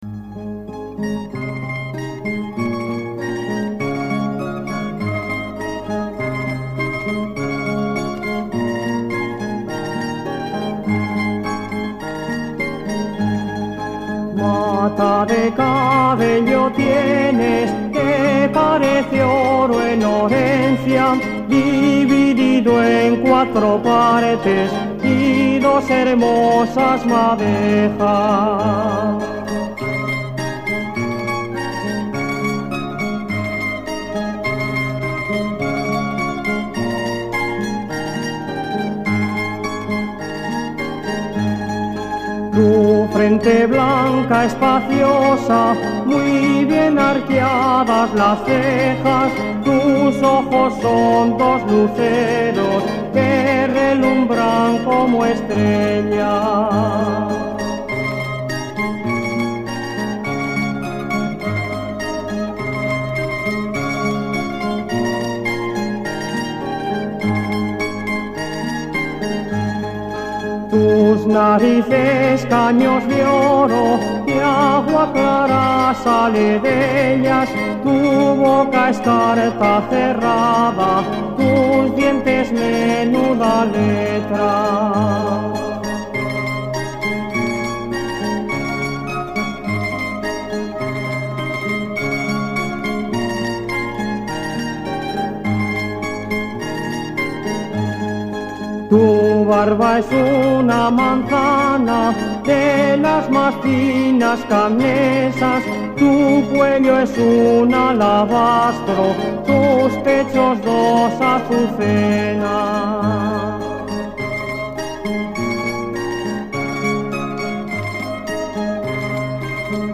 albada_embun.mp3